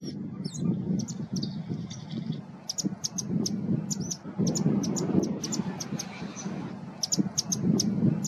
White-crested Tyrannulet (Serpophaga subcristata)
Life Stage: Adult
Detailed location: Camino cercano a la Laguna de Guatraché.
Condition: Wild
Certainty: Photographed, Recorded vocal